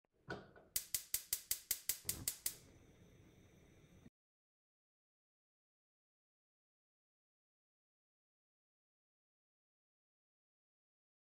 Igniting a Gas Stove
Kitchen Appliances
Igniting-a-Gas-Stove-Free-Sound-effect-SFX-1.mp3